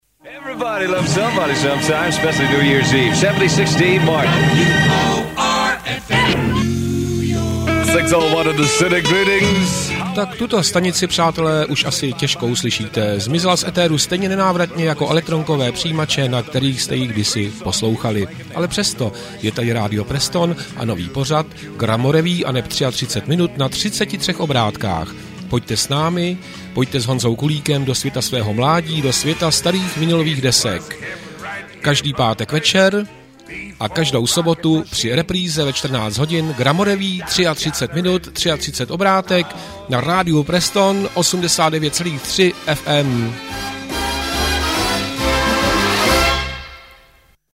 Upoutávky jsou v podstatě delšími jingly, sloužící jako promotion, většinou pravidelného pořadu.
Upoutávka na první sérii pořadu asi do čísla #35.